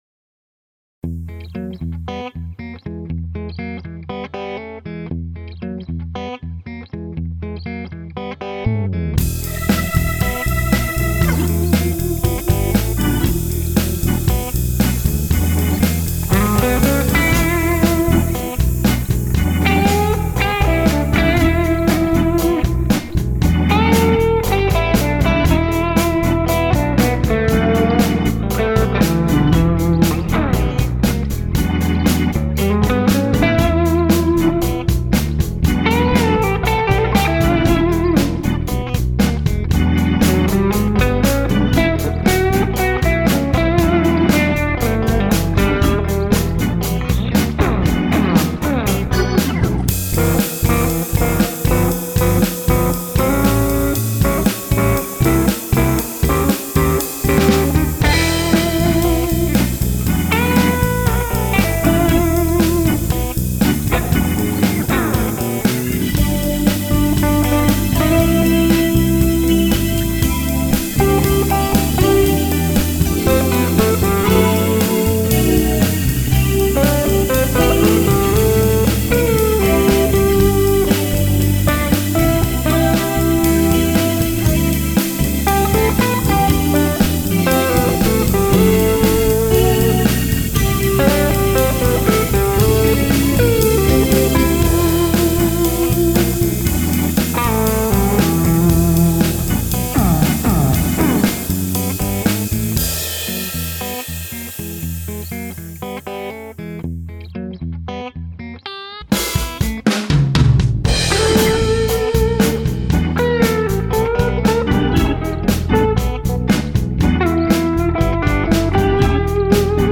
drums
copy my original guitar solo in reverse
bass
organ part